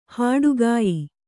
♪ hāḍugāyi